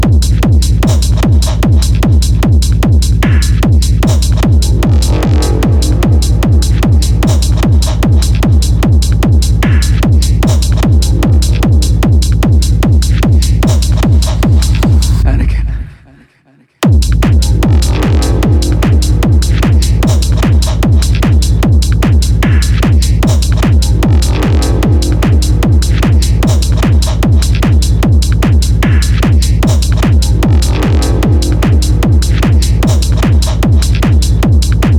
Жанр: Техно